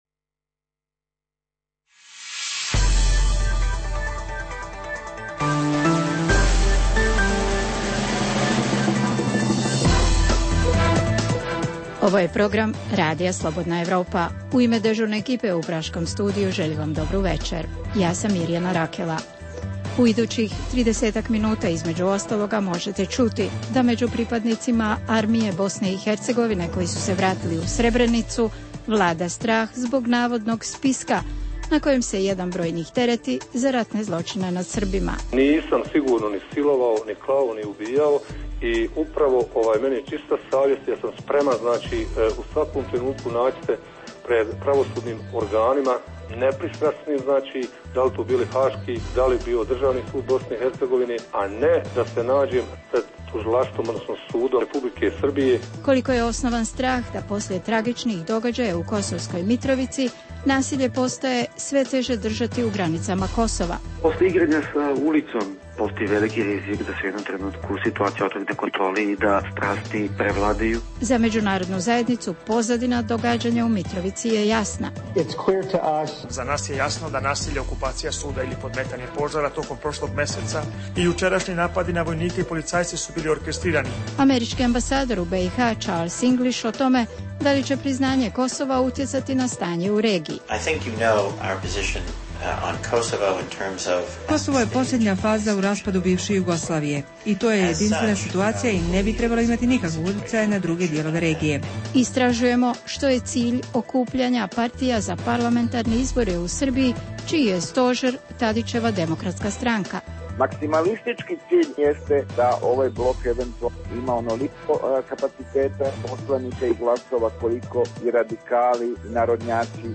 U programu analiziramo: postoje li u Srbiji nalozi za hapšenja građana Srebrenice, može li se nasilje iz Kosovske Mitrovice proširiti i na druge dijelove Kosova, objavljujemo interview s američkim ambasadorom SAD u BiH Charles L. Englishom. Pokušat ćemo odgovoriti što je u pozadini dojava o lažnim bombama u Srbiji i Crnoj Gori.